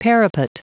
Prononciation du mot parapet en anglais (fichier audio)
Prononciation du mot : parapet